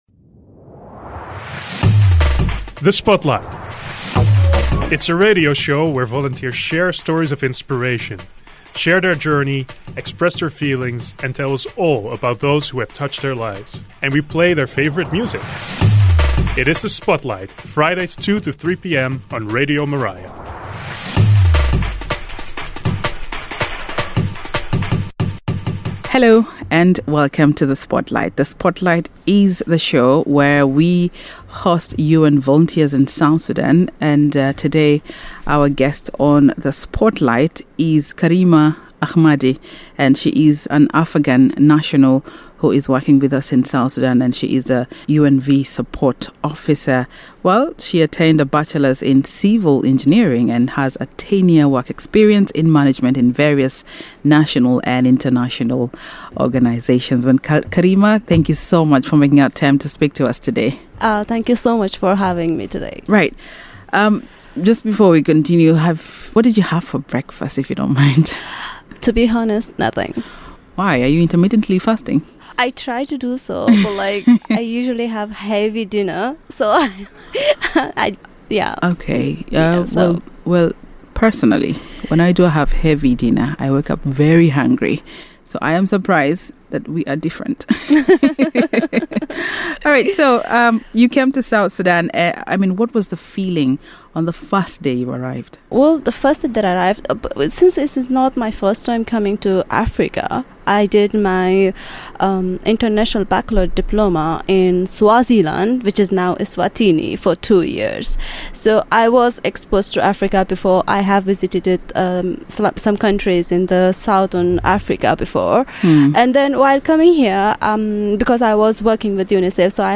The Spotlight Program is a radio show where volunteers share their stories of inspiration from 14:00 to 15:00 every Friday on Radio Miraya.